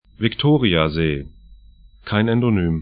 Aussprache
Victoriasee vɪk'to:rĭaze: Sango 'zaŋgo